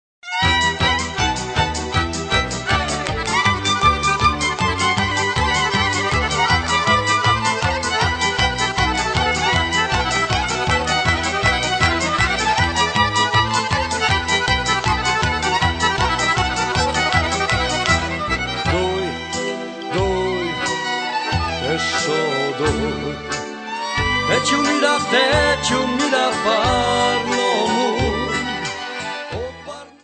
3 CD Set of Polish Gypsy Music.